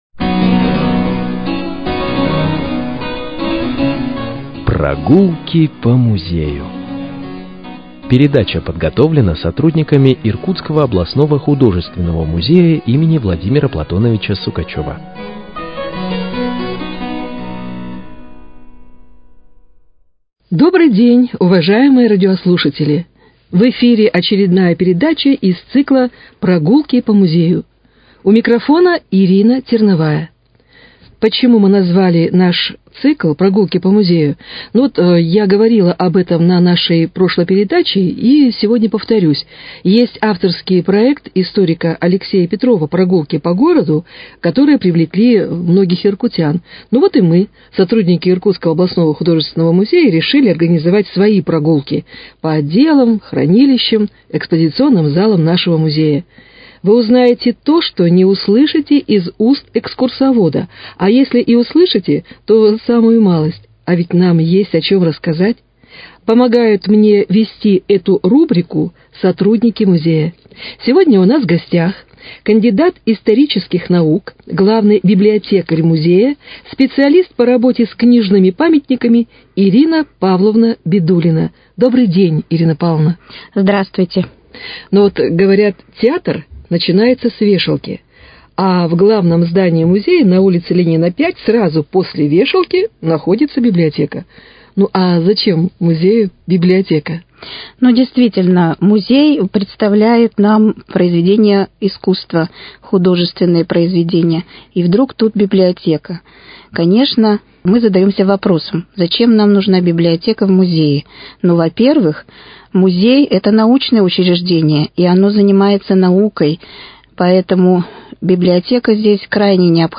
Прогулки по музею: Беседа с с главным библиотекарем художественного музея